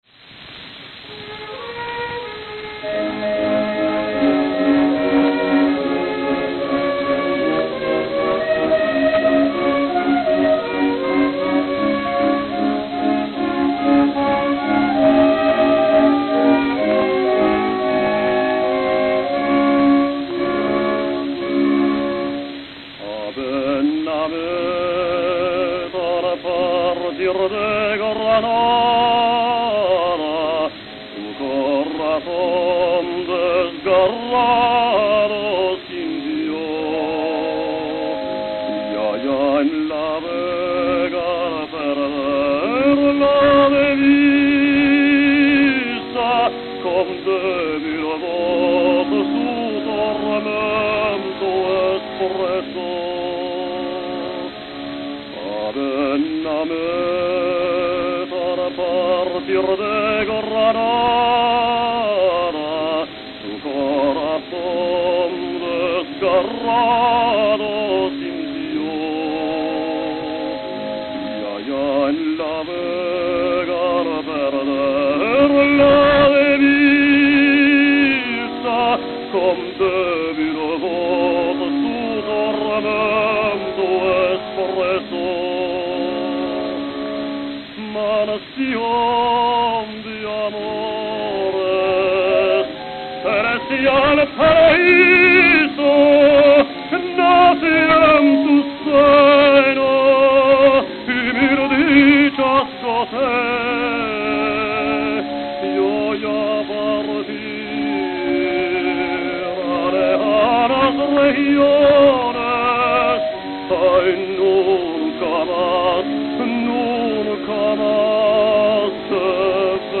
Philadelphia, Pennsylvania (?)